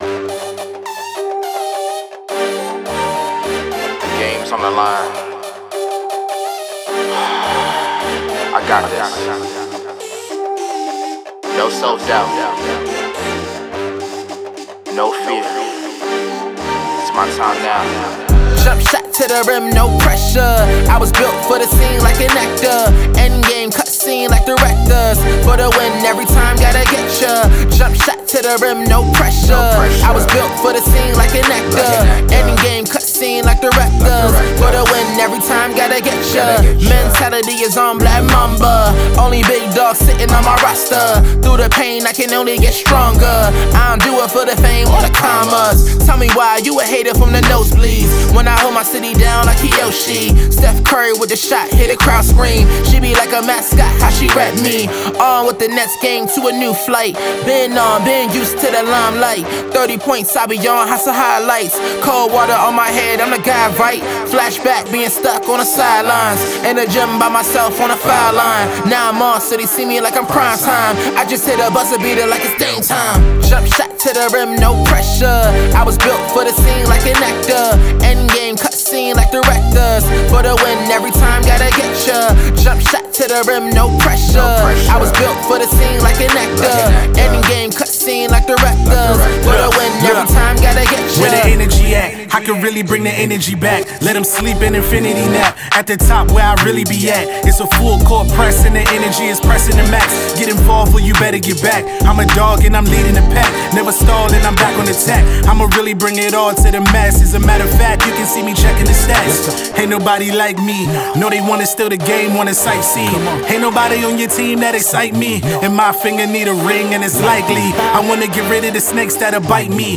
Update gym song for athletes.